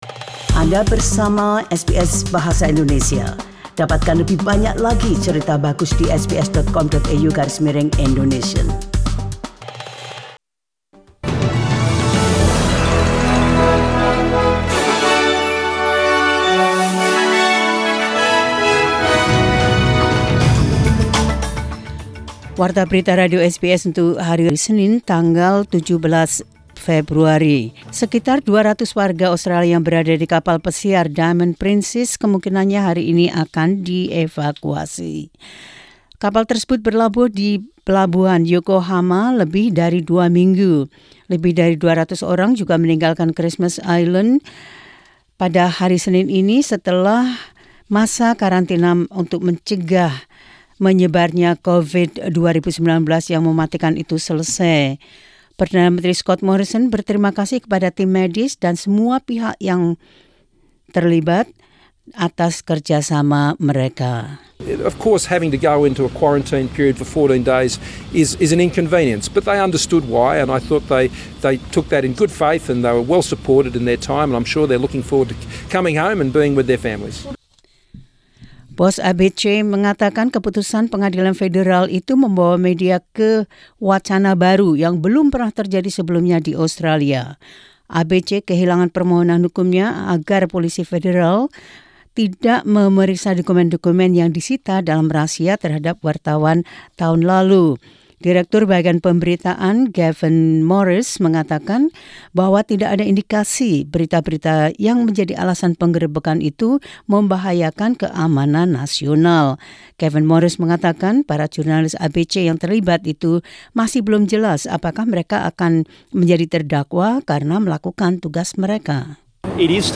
SBS Radio News in Indonesian 17 Feb 2020.